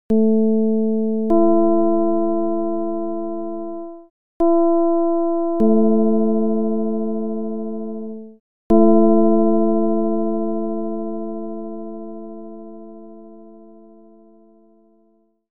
File:Ji-49-32-csound-foscil-220hz.mp3 - Xenharmonic Wiki
sound example for 49/32